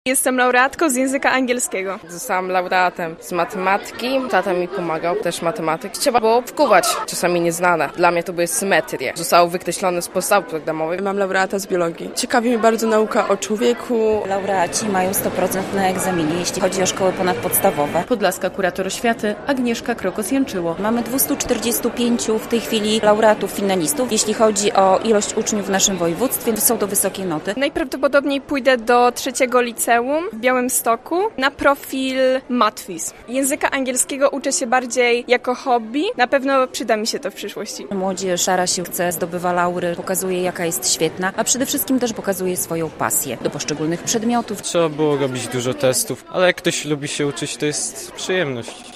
Najlepsi olimpijczycy z nagrodami od podlaskiej kurator oświaty - relacja